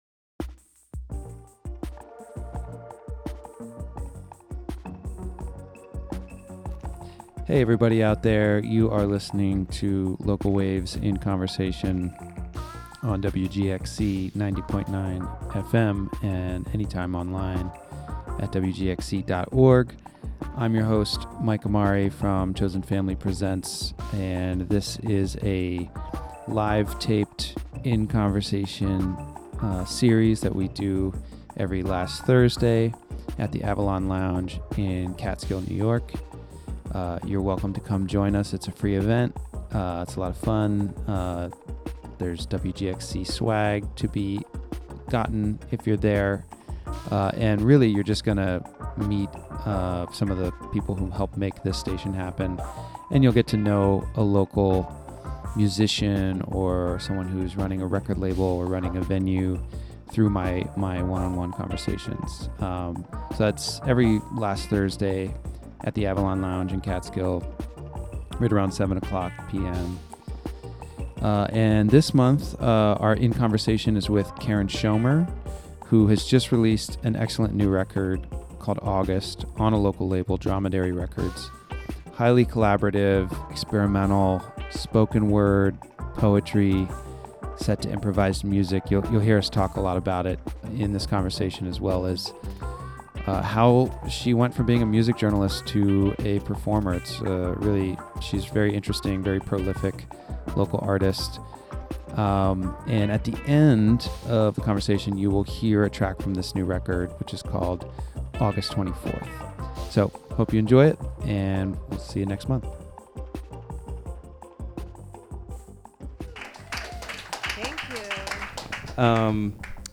sits down for a short discussion with local musicians, record labels, recording engineers, venue owners, and more to dig deeper into the undercurrent of activity.